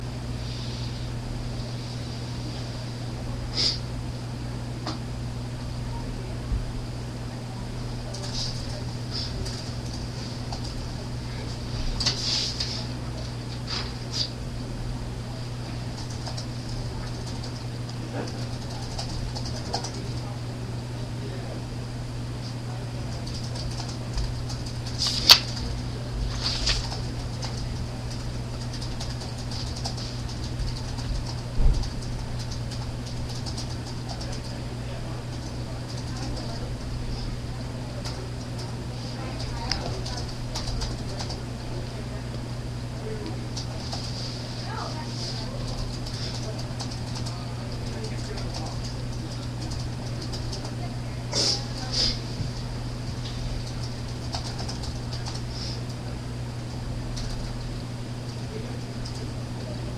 Field Recording 1
LOCATION: Republic Hall study room
SOUNDS HEARD: sniffling, typing on keyboard, mouse clicking, chatter outside, computer hum, heating unit hum, pages shuffling, pages turning